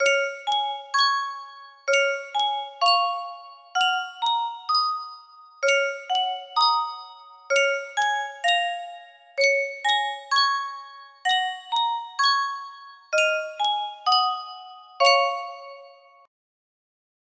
Yunsheng Music Box - Beethoven Fidelio 5802 music box melody
Full range 60